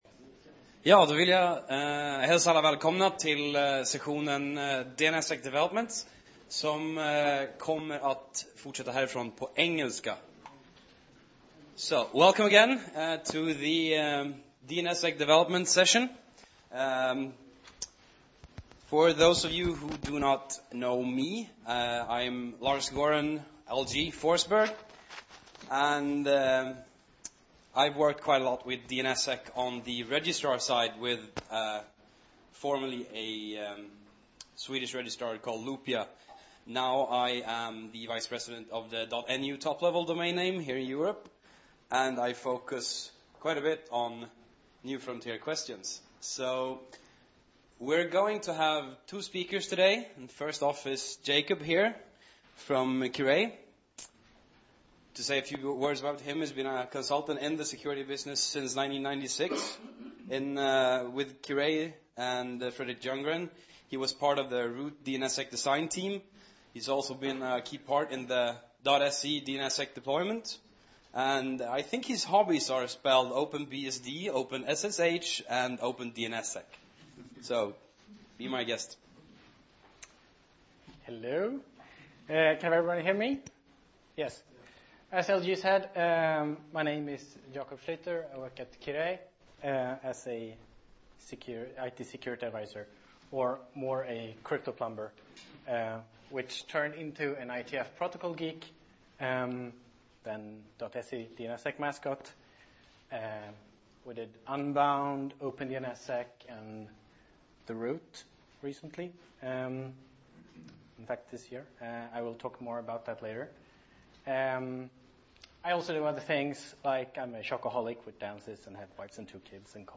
DNSSEC developments Plats: Rum 300 Datum: 2010-10-26 Tid: 13:40-15:10 2010 seems set to become the year when DNSSEC breaks through.